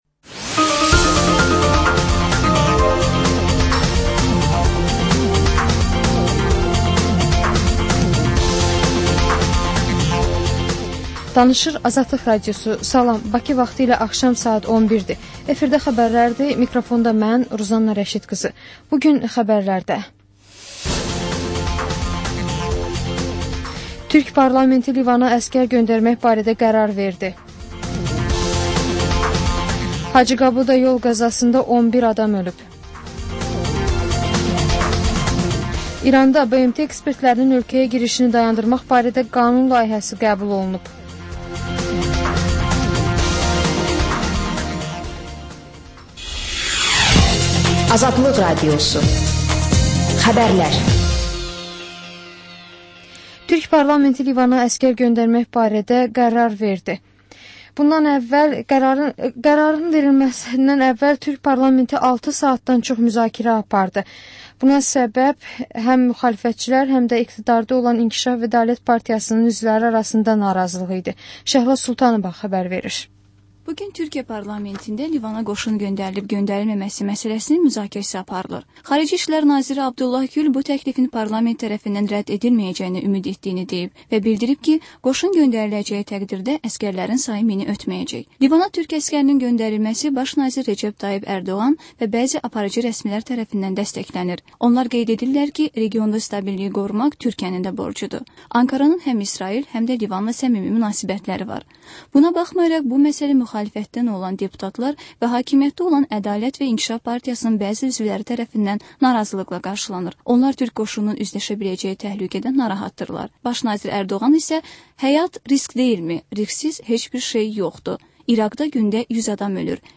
Xəbərlər, reportajlar, müsahibələr. Hadisələrin müzakirəsi, təhlillər, xüsusi reportajlar. Və sonda: Şəffaflıq: Korrupsiya barədə xüsusi veriliş.